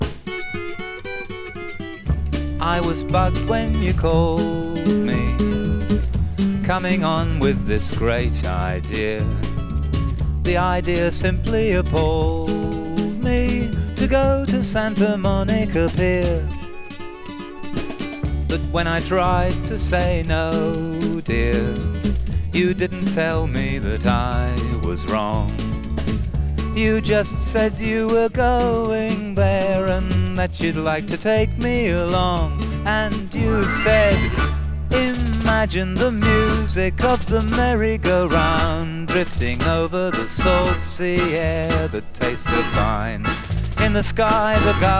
力の抜けた歌い回しは魅力的。
Rock, Folk Rock　USA　12inchレコード　33rpm　Stereo